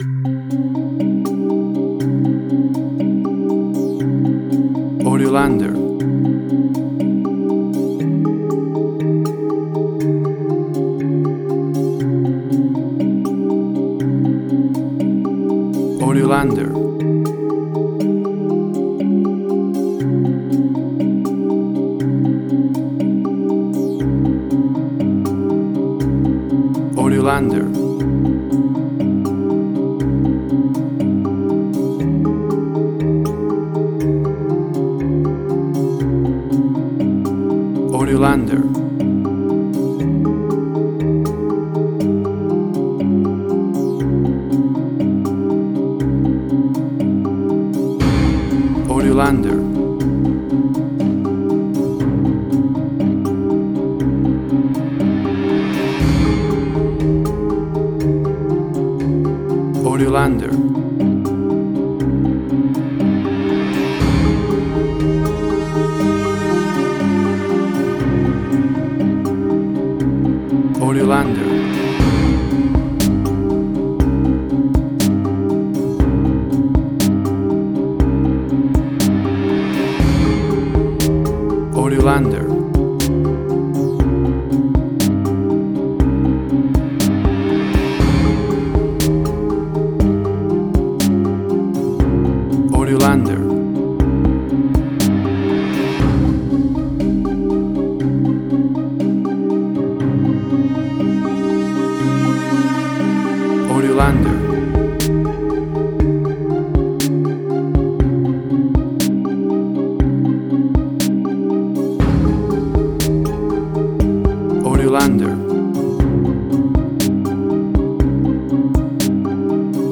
Suspense, Drama, Quirky, Emotional.
Tempo (BPM): 120